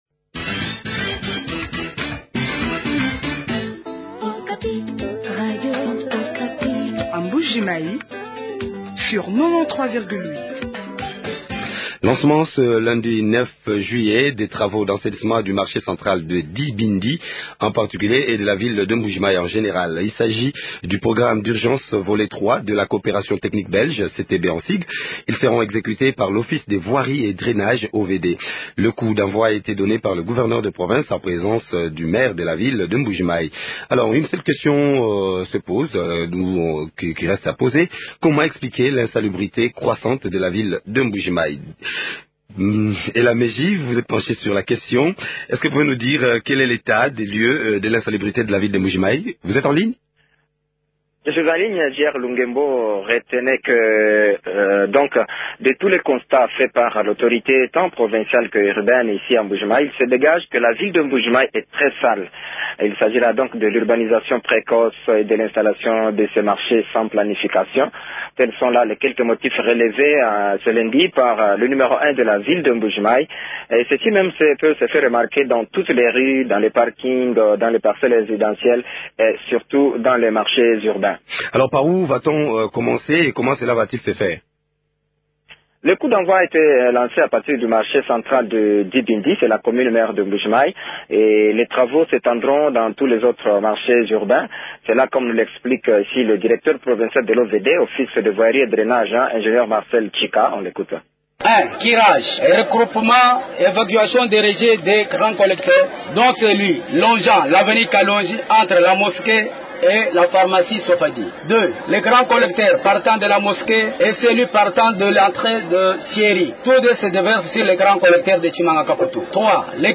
font le point de la question avec Marie Thérèse Tshisaka, Maire a.i de Mbujimayi.